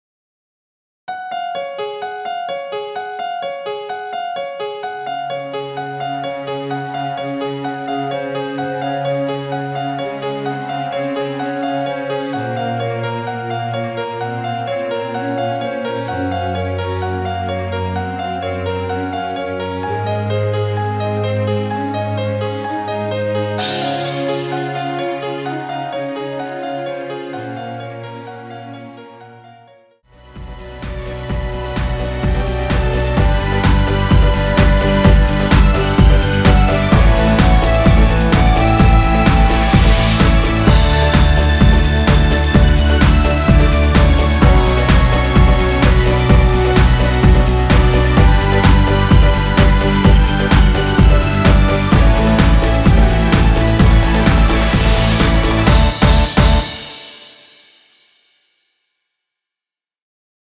원키 멜로디 포함된 MR 입니다.
엔딩이 페이드 아웃이라 엔딩을 만들어 놓았습니다.(미리듣기 확인)
Db
앞부분30초, 뒷부분30초씩 편집해서 올려 드리고 있습니다.
중간에 음이 끈어지고 다시 나오는 이유는